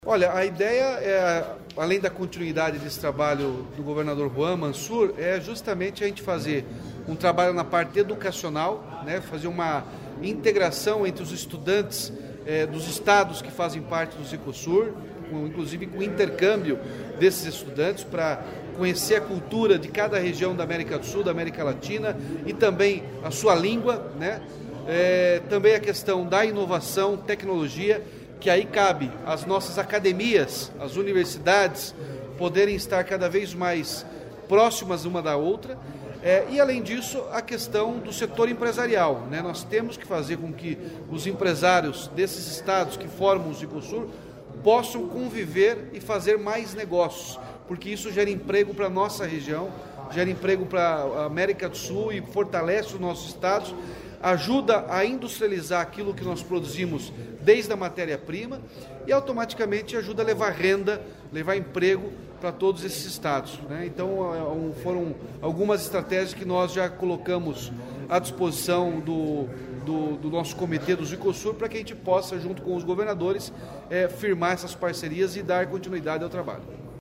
Sonora do governador Ratinho Junior sobre a presidência do Zicosur